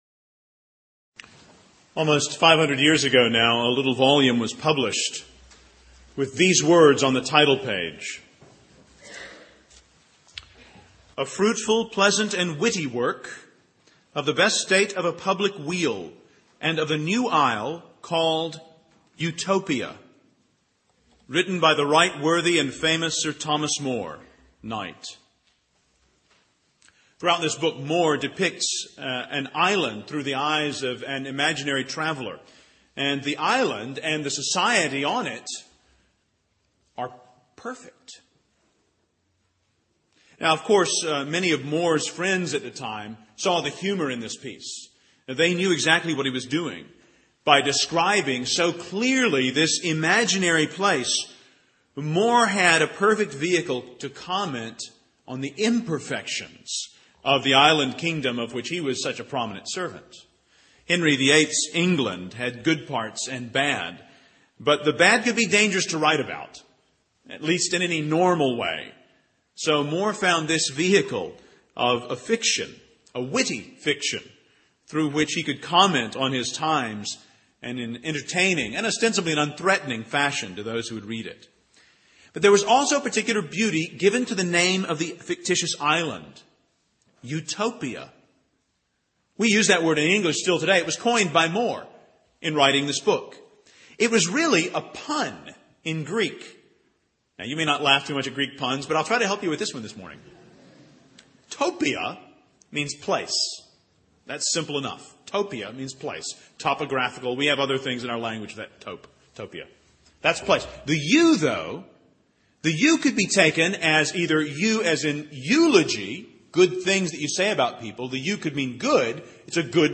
Teaching & Preaching